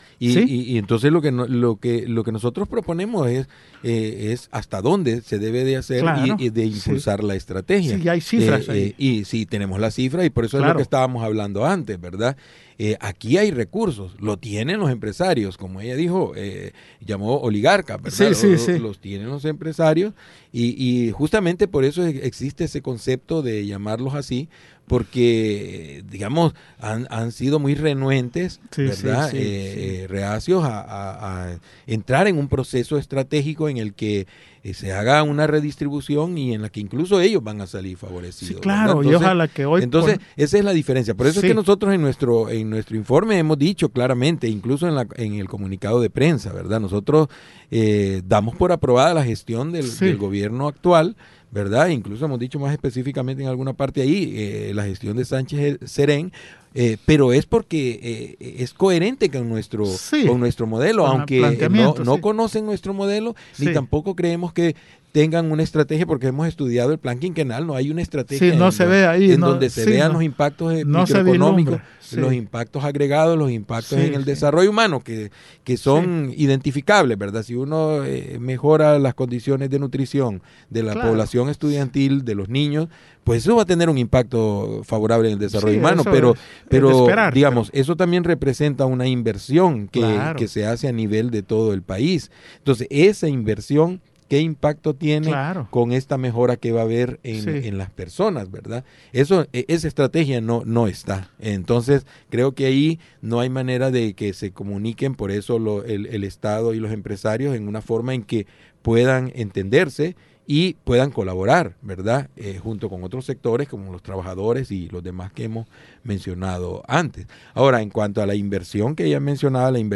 Programa de radio: Hablando de Economía